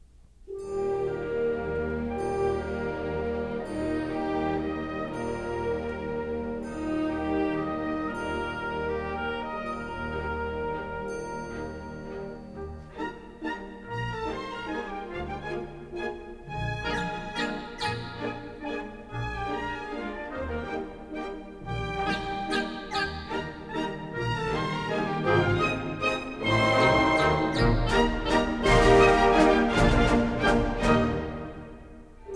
Stereo recording made in April 1957 in the
Orchestral Hall, Chicago